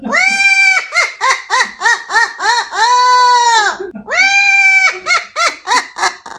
Звуки смеха